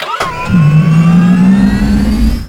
tank_start.wav